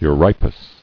[eu·ri·pus]